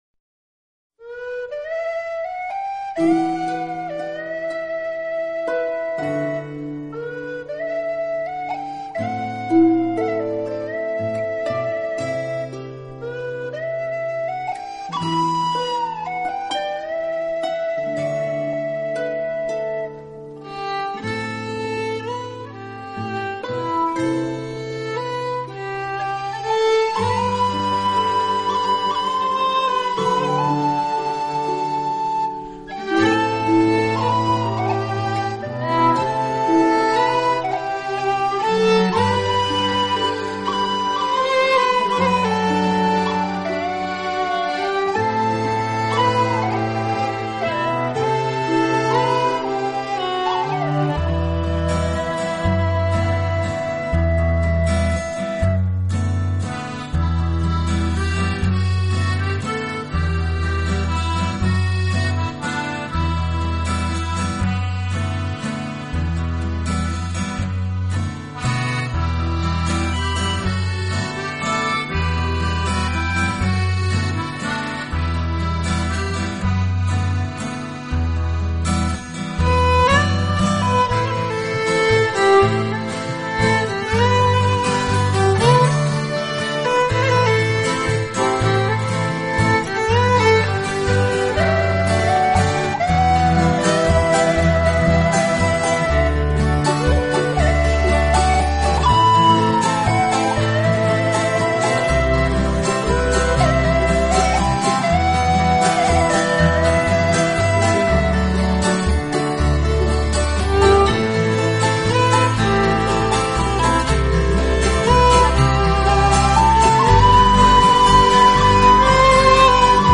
音乐风格: Celtic